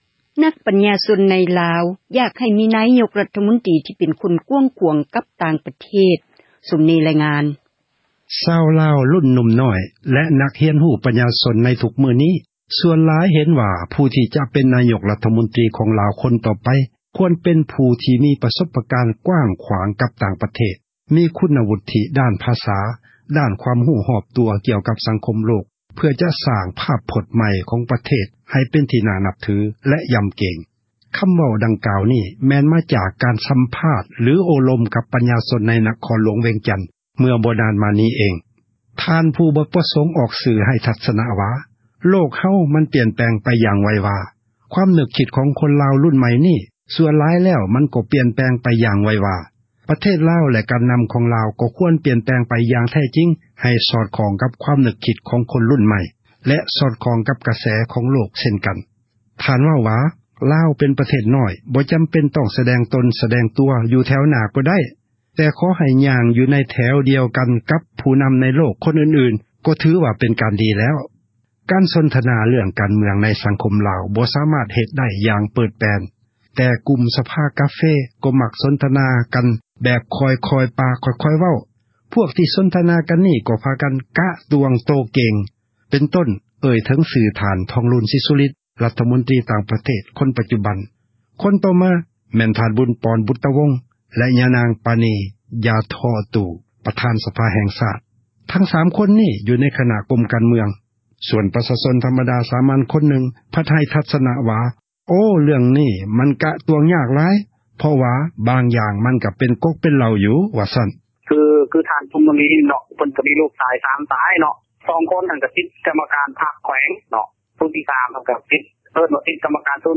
ຄໍາເວົ້າ ນີ້ ແມ່ນ ມາຈາກ ການ ສັມພາດ ໂອ້ລົມ ກັບ ປັນຍາຊົນ ໃນ ນະຄອນ ຫລວງ ວຽງຈັນ ເມື່ອ ບໍ່ດົນ ມານີ້.